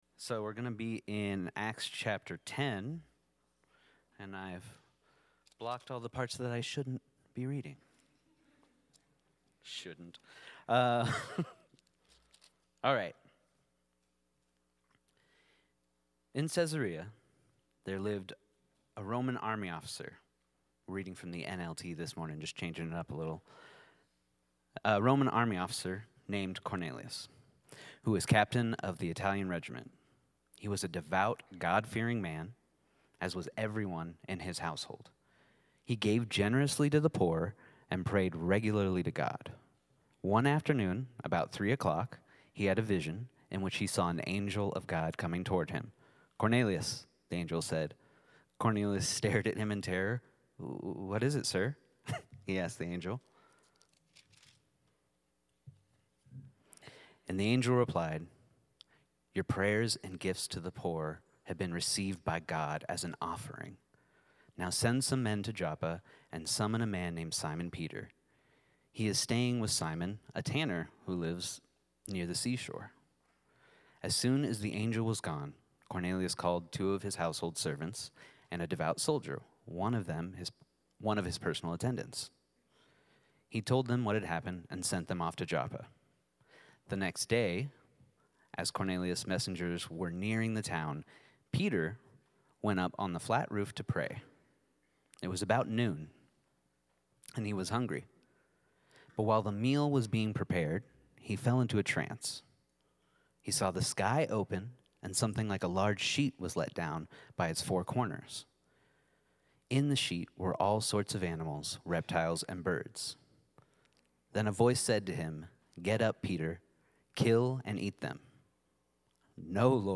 A message from the series "Revival Times."